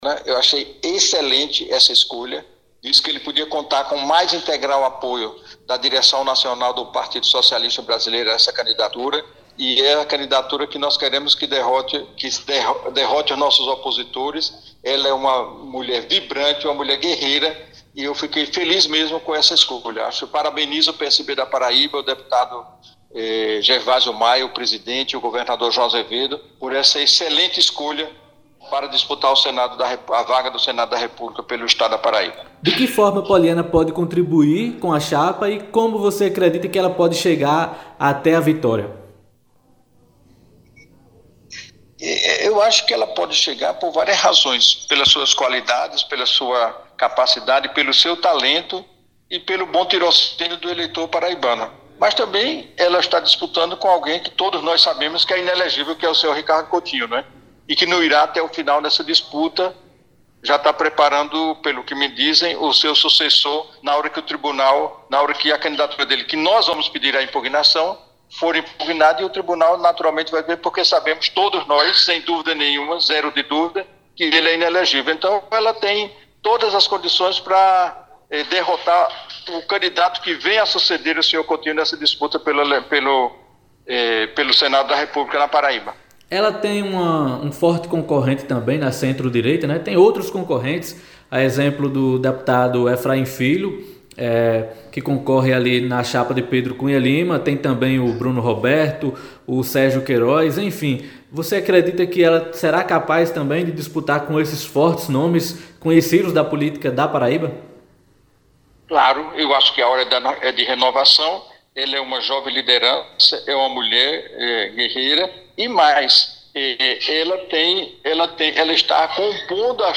O presidente Nacional do PSB, Carlos Siqueira, informou nesta terça-feira (02), que a legenda ingressará com uma ação na Justiça Eleitoral pedindo a impugnação do registro de candidatura do ex-governador Ricardo Coutinho (PT) ao Senado nas eleições deste ano, na Paraíba. Em entrevista